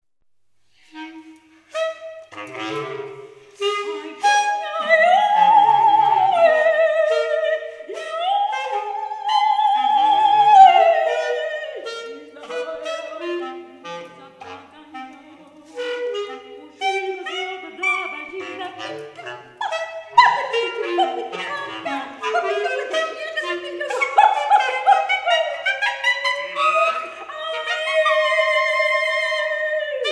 Improvisation Voice and Sax
ImprovisationVoiceAndSax.mp3